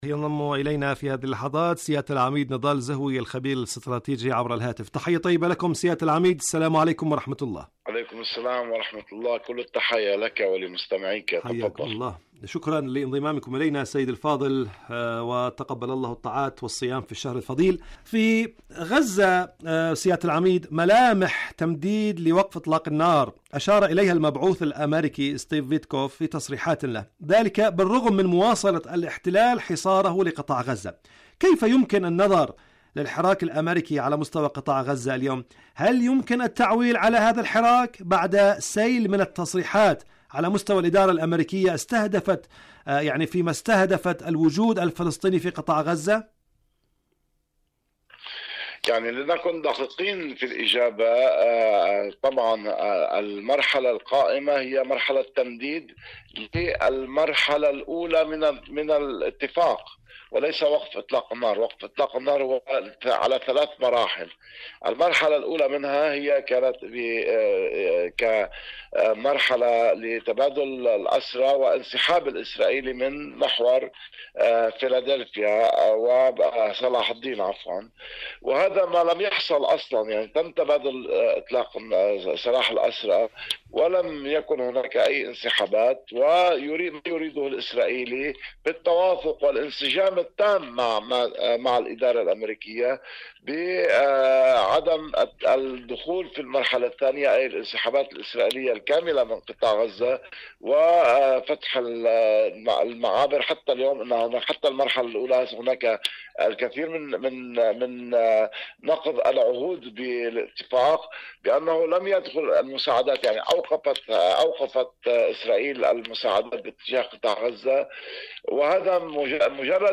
مقابلات برامج إذاعة طهران العربية برنامج فلسطين اليوم مقابلات إذاعية القدس الشريف كيان الاحتلال المسجد الاقصى غزة-لبنان حلول المؤقته أم نهائية؟